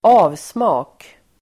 Uttal: [²'a:vsma:k]